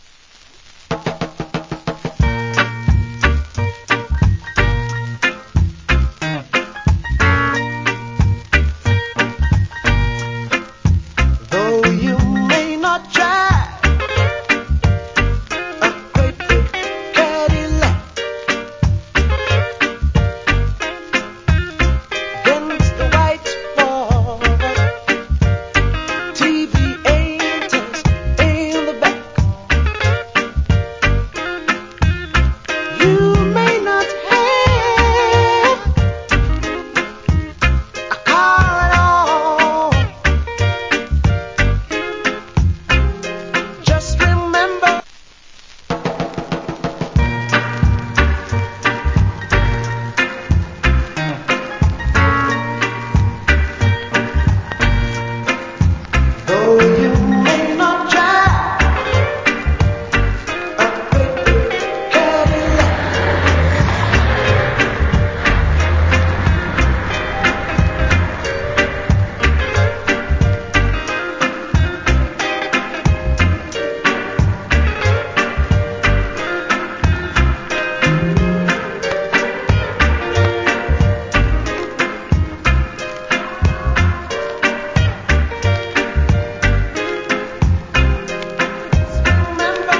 Good Cover. Cool Reggae Vocal.